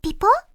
pause-continue-click.ogg